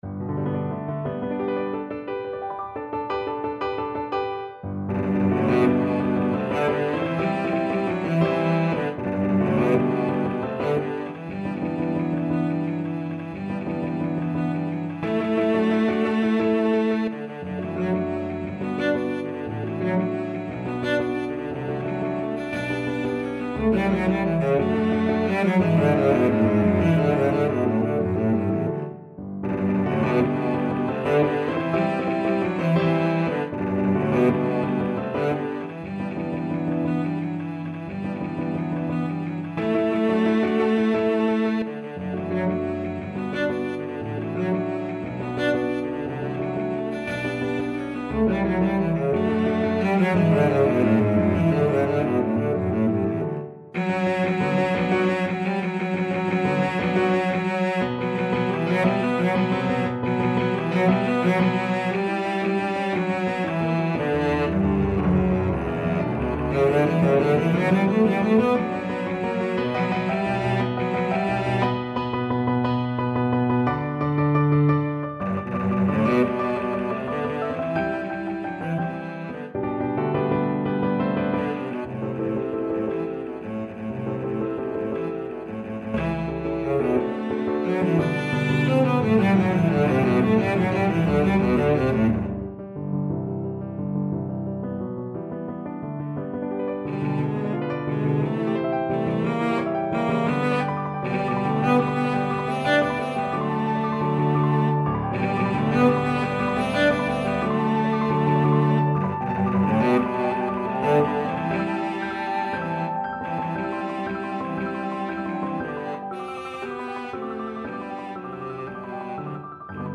Cello version
6/8 (View more 6/8 Music)
D3-D5
Classical (View more Classical Cello Music)